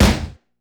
DrSnare42.wav